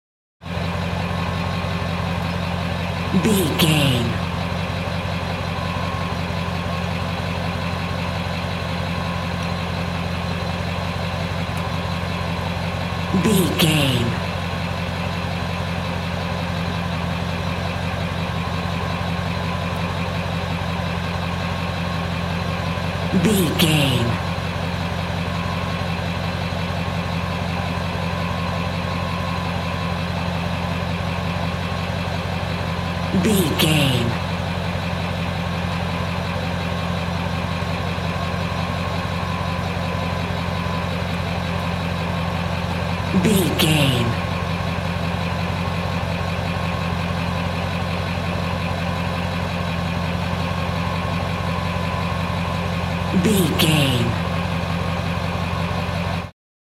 Ambulance Ext Diesel Engine Idle Exhaust
Sound Effects
chaotic